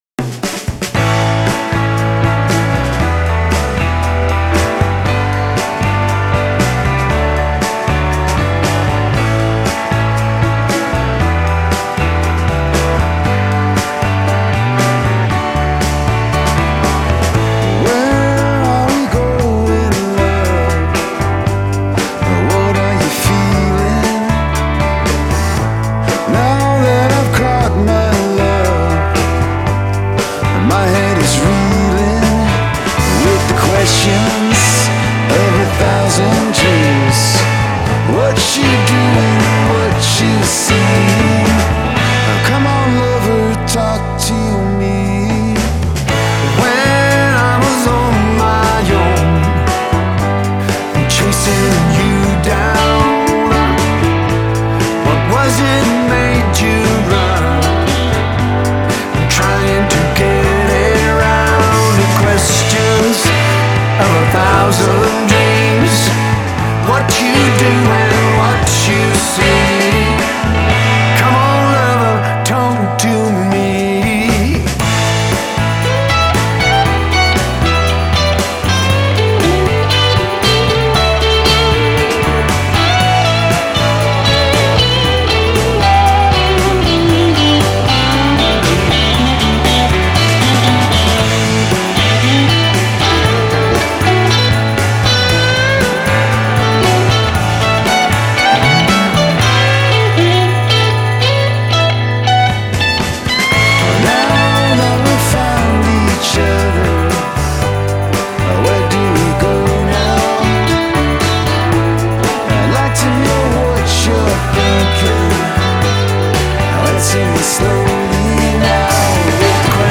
Soundtrack is outstanding.
fills are so clean and it sounds so good on the headphones